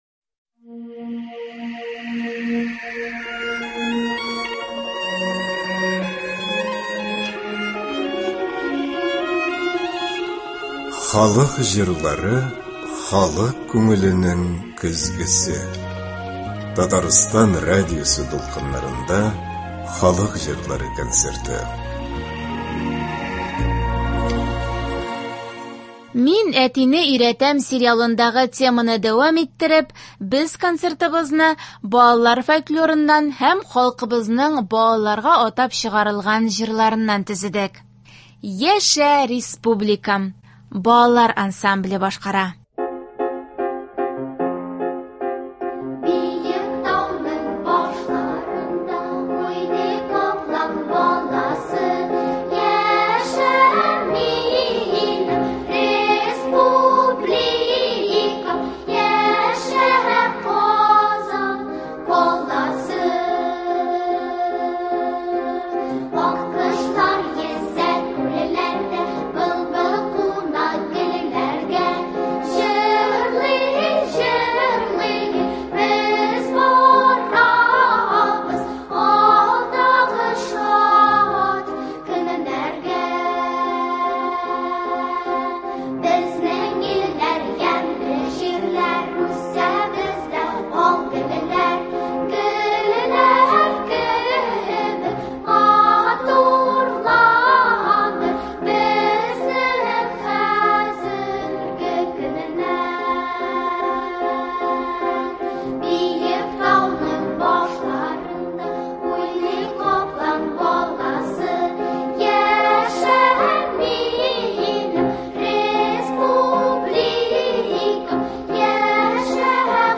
Татар халык көйләре (02.12.23)